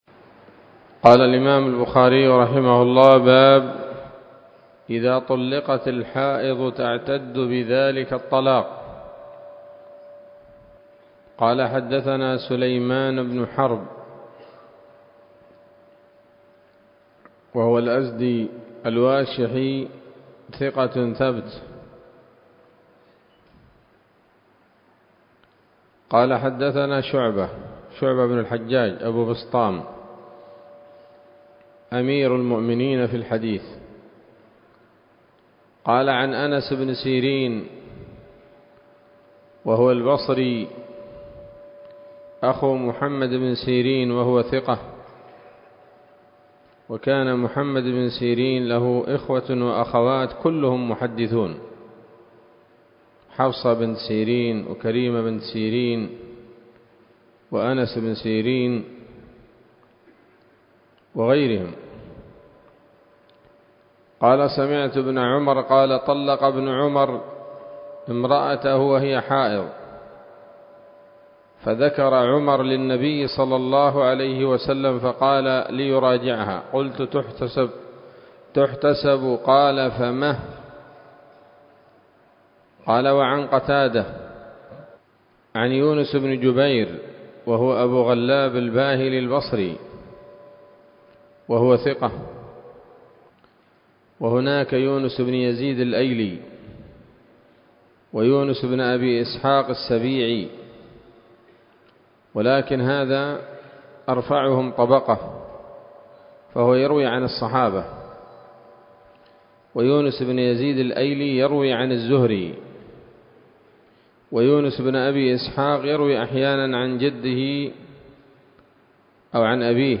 الدرس الثاني من كتاب الطلاق من صحيح الإمام البخاري